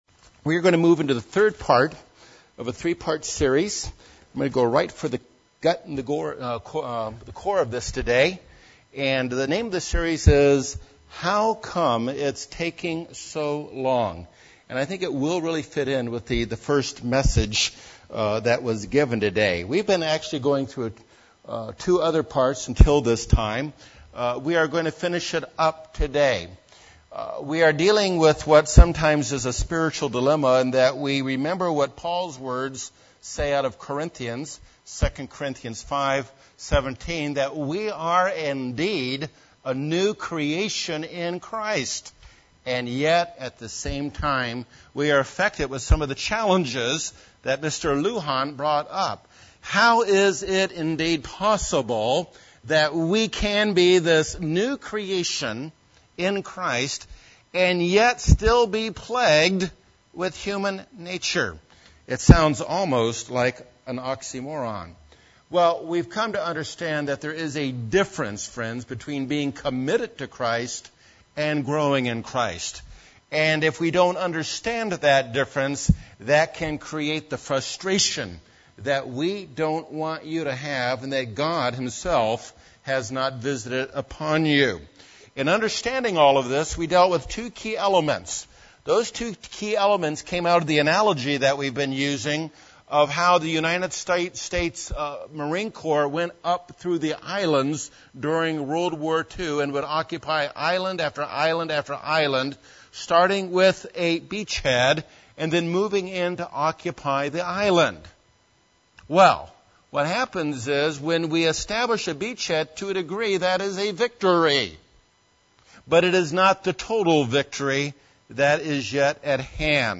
UCG Sermon Transcript This transcript was generated by AI and may contain errors.